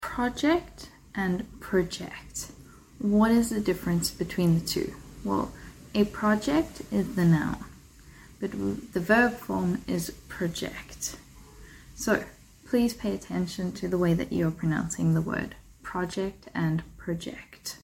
Project as a Noun: Pronunciation: sound effects free download
Project as a Noun: Pronunciation: /ˈprɒdʒɛkt/ (PRO-jekt) Meaning: Refers to an individual or collaborative enterprise that is carefully planned to achieve a particular aim.
Project as a Verb: Pronunciation: /prəˈdʒɛkt/ (pro-JEKT) Meaning: Means to estimate or forecast something based on current trends or data.